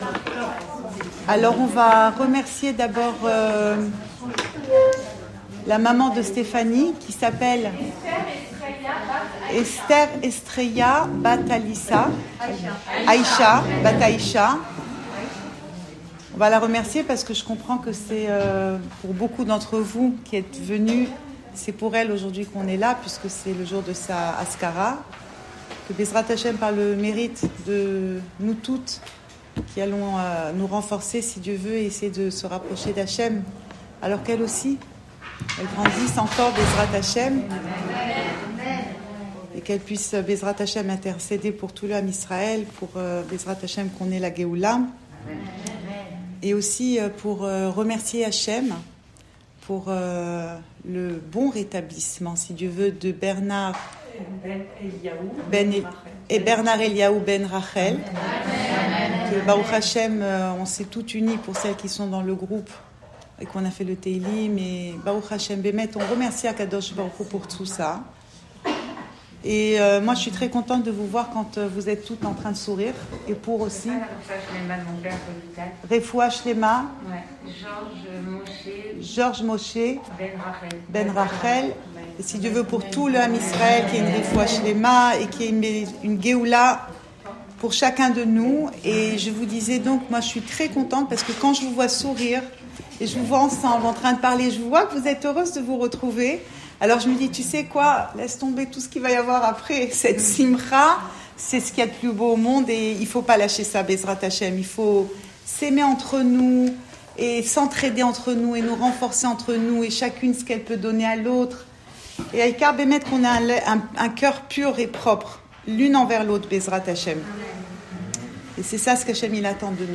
Cours audio Le coin des femmes Pensée Breslev - 14 octobre 2020 14 octobre 2020 Berechit : Le monde entier attend notre travail. Enregistré à Tel Aviv